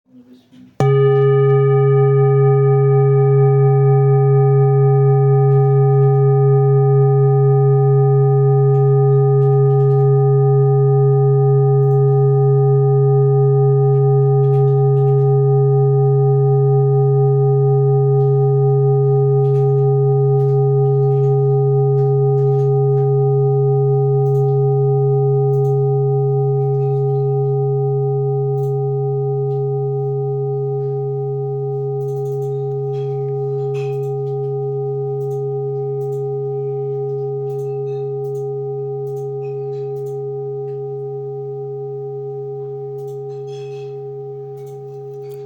Jambati Singing Bowl, Buddhist Hand Beaten, with Fine Etching Carving
Material Seven Bronze Metal
Jamabati bowl is a hand-beaten bowl.
It can discharge an exceptionally low dependable tone.